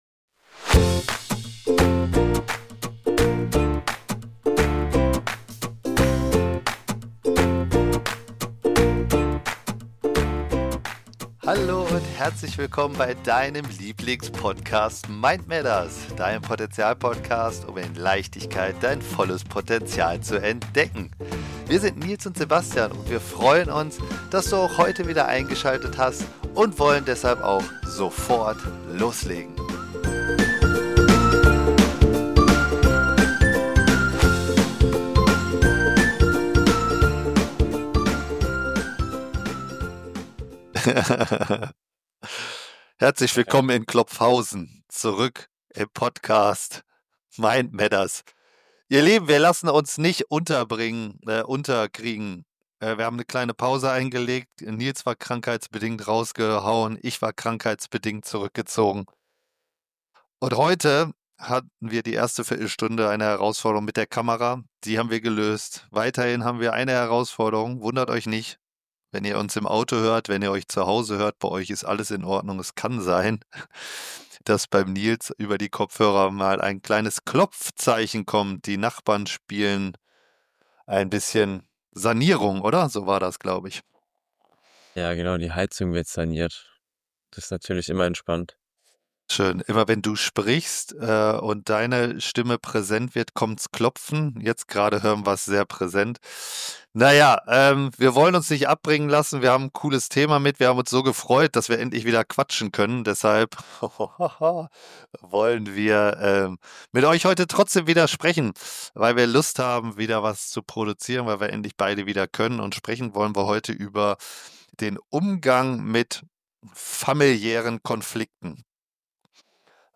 Sie beleuchten die Herausforderungen, die durch unterschiedliche Familienwelten entstehen, und betonen die Bedeutung von Selbstreflexion, Verständnis und Empathie. Die beiden Moderatoren ermutigen die Zuhörer, Verantwortung für ihre eigenen Themen zu übernehmen und präventive Ansätze zu entwickeln, um zukünftige Konflikte zu minimieren. Abschließend wird der schmale Grat zwischen Verständnis für andere und Selbstschutz thematisiert, sowie ein Ausblick auf zukünftige Themen gegeben.